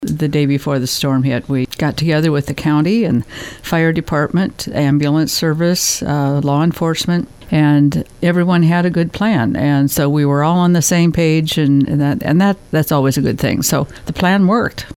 Mayor Gloria Hanson says the response was a combined effort.